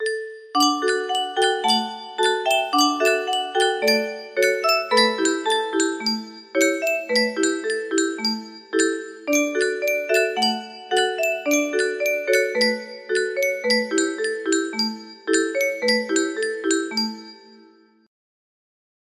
accompaniment to play in a loop while singing
Grand Illusions 30 music boxes More